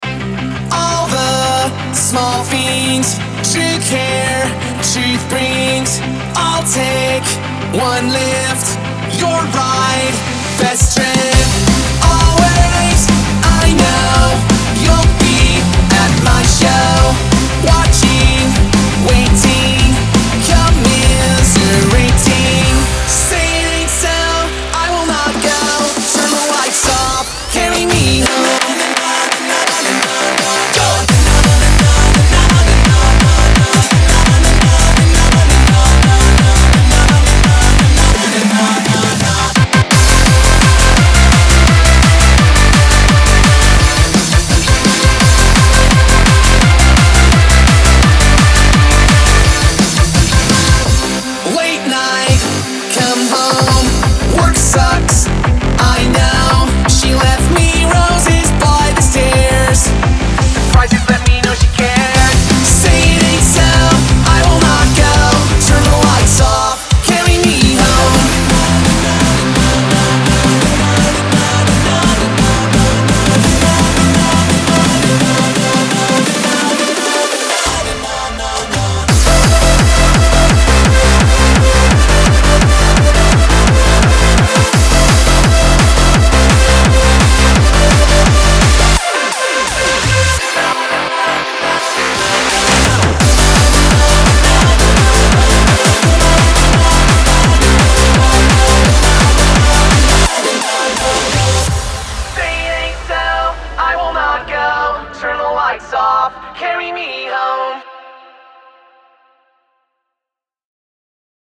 • Жанр: Hardstyle